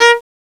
Index of /m8-backup/M8/Samples/Fairlight CMI/IIX/STRINGS1